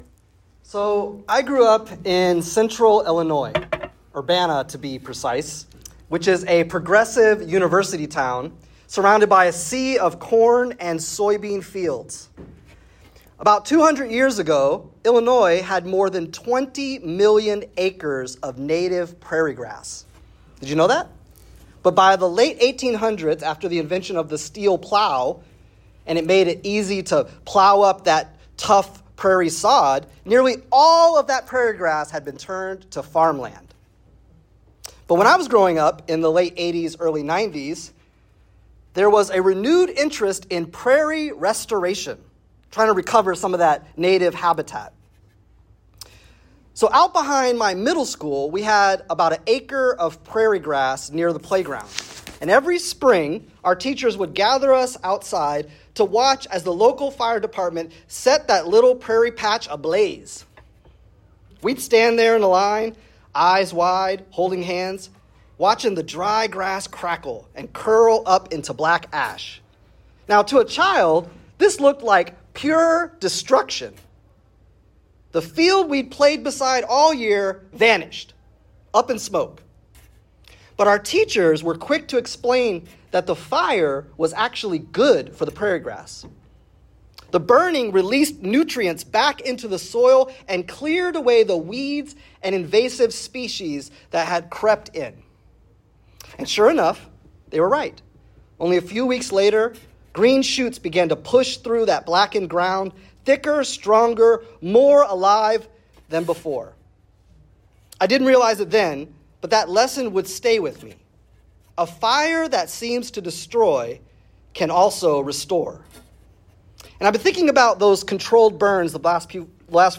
This sermon explores the theme of judgment in Revelation through the image of a controlled prairie burn—fire that looks destructive but actually restores life.